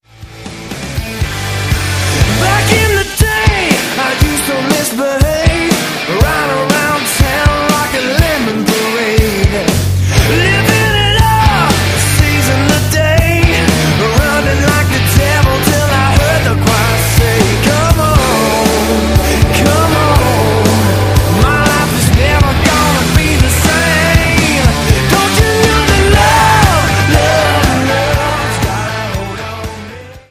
• Sachgebiet: Rock